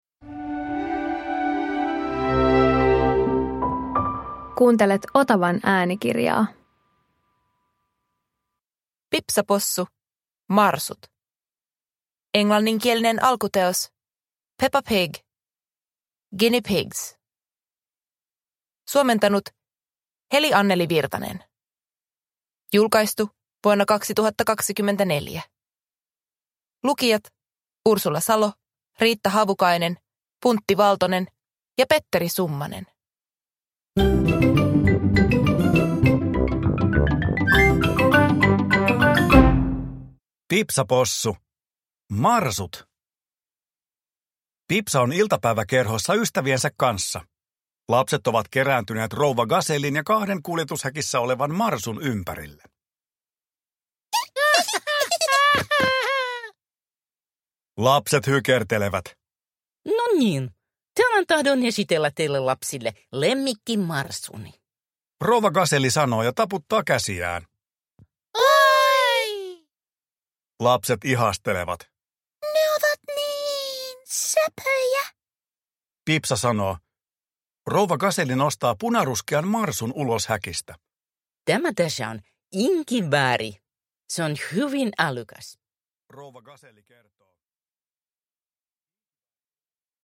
Pipsa Possu - Marsut – Ljudbok